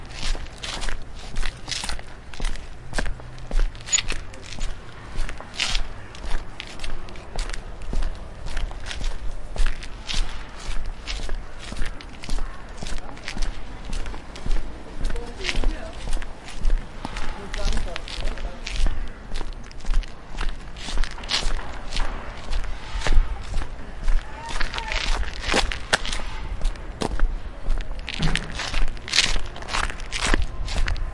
混凝土的脚步声 " 混凝土16a trekkingshoes行走
描述：走在混凝土上：徒步鞋。在房子的地下室用ZOOM H2记录，用Audacity标准化。
标签： 脚步 步骤 步骤 混凝土 走路 踏板 步行
声道立体声